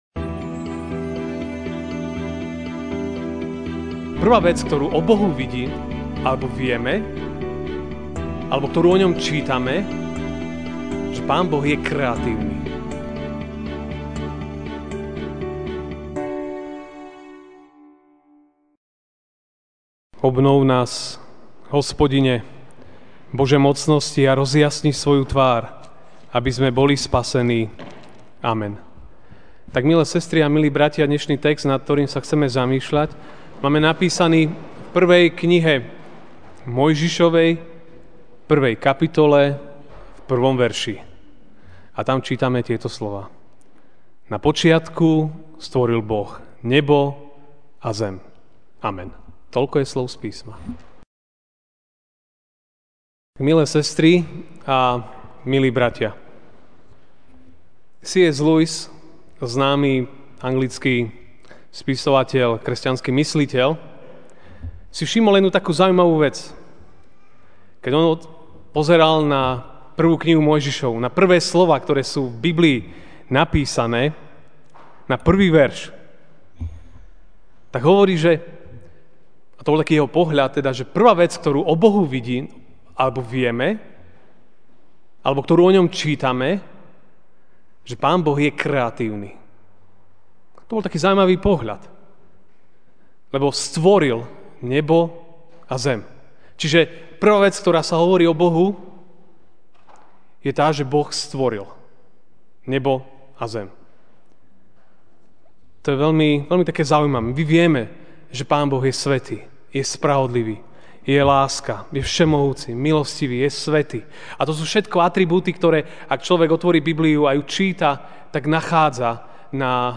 jún 25, 2017 Kreatívny Boh MP3 SUBSCRIBE on iTunes(Podcast) Notes Sermons in this Series Ranná kázeň: Kreatívny Boh (1.